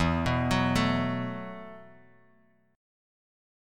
Esus2sus4 chord